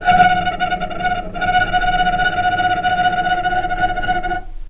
healing-soundscapes/Sound Banks/HSS_OP_Pack/Strings/cello/tremolo/vc_trm-F#5-pp.aif at 01ef1558cb71fd5ac0c09b723e26d76a8e1b755c
vc_trm-F#5-pp.aif